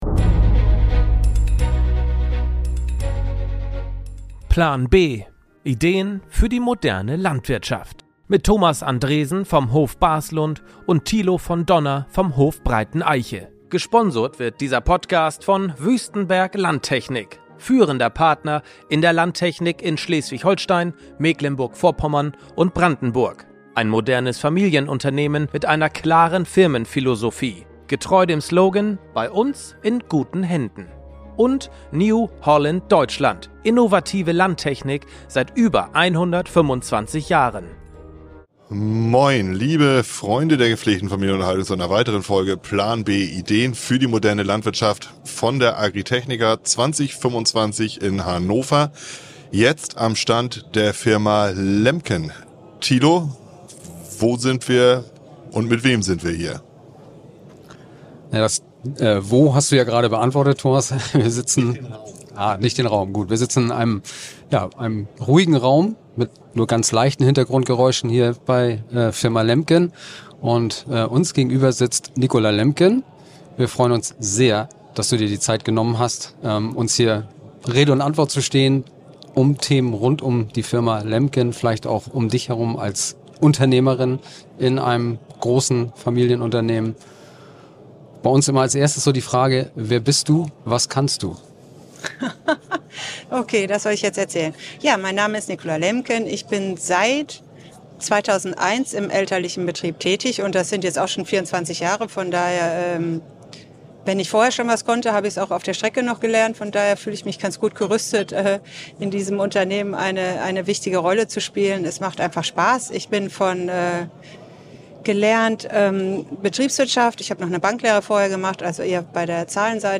live auf der Agritechnica ~ Plan B - Ideen für die moderne Landwirtschaft Podcast